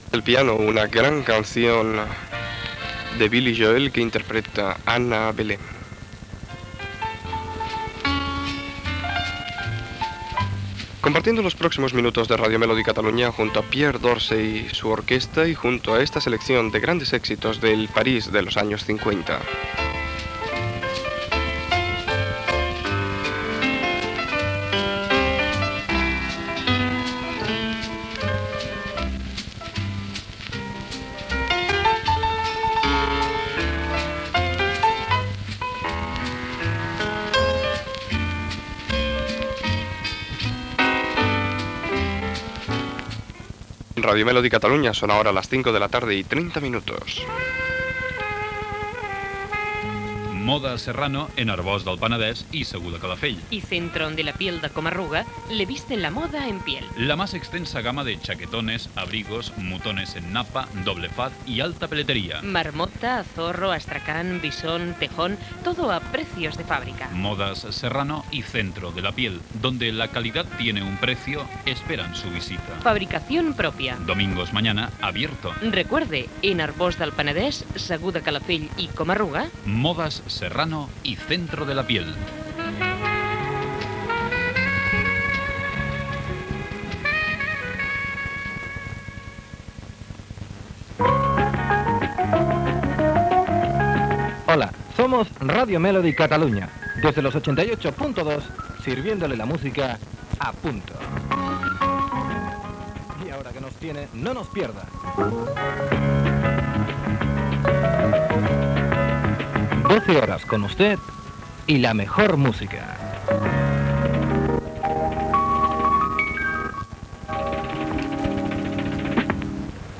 Identificació, tema musical, publicitat, indicatiu i tema musical.
Musical
FM